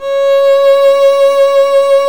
Index of /90_sSampleCDs/Roland - String Master Series/STR_Violin 1 vb/STR_Vln1 _ marc